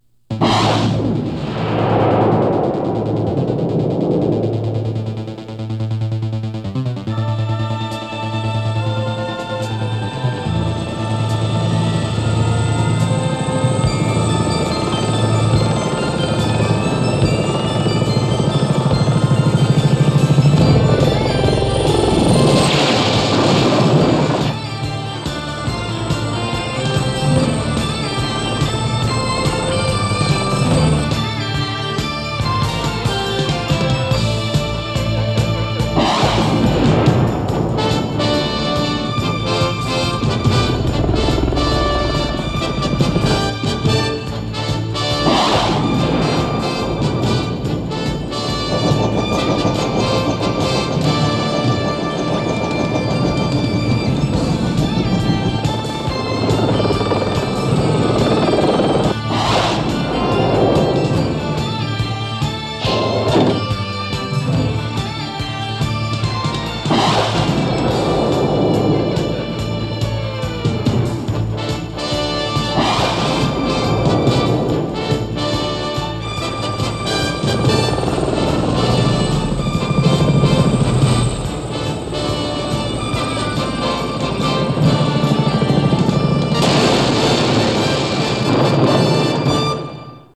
Theme music (.wma)